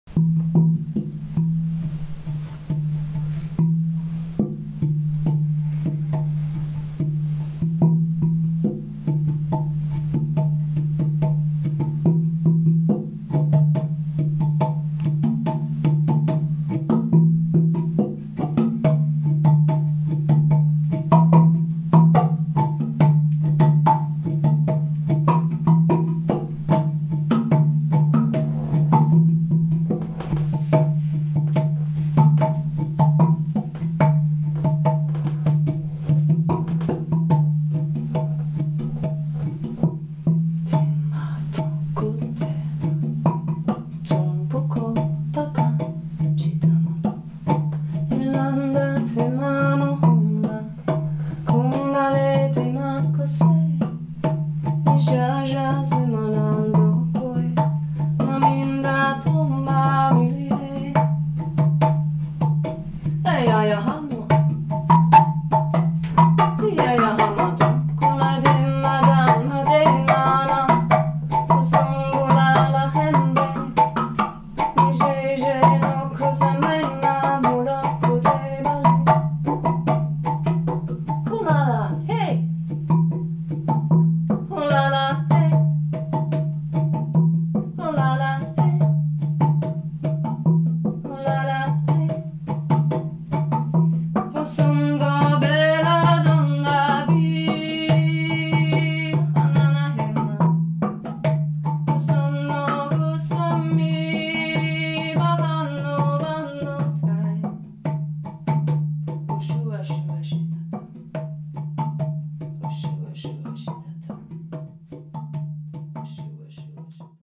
Als Vorgeschmack findest Du hier eine kurze inspirierende Hörprobe einer ca. 60-minütigen Jam-Session
Es war eine wohltuende Freude dies live erleben zu dürfen.